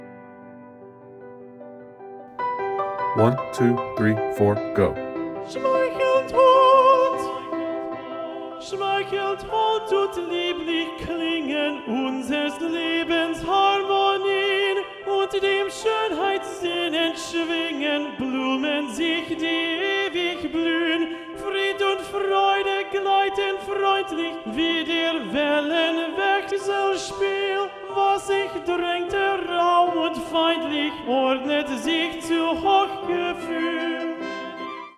Alto Vocal Model Recording (all Altos will sing the Alto, bottom note, not the Soprano 2, middle note)